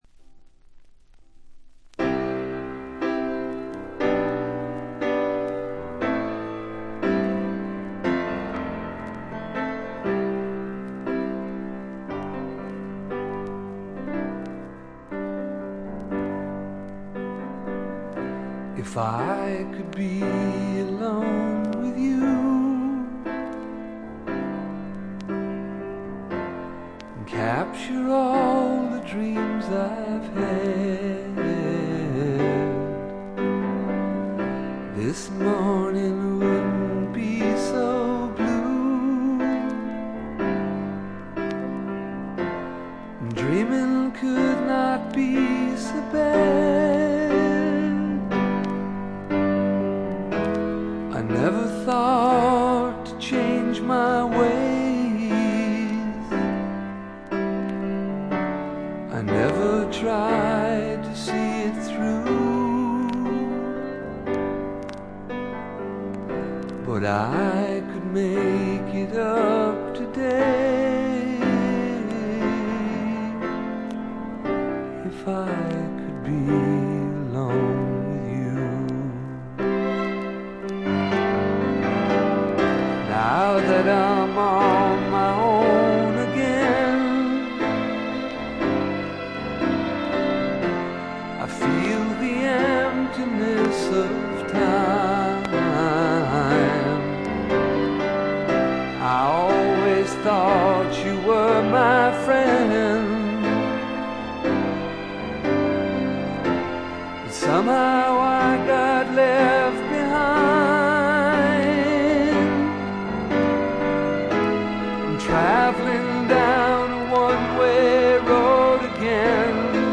バックグラウンドノイズ、チリプチがやや多め大きめです。
試聴曲は現品からの取り込み音源です。
Vocals, Acoustic Guitar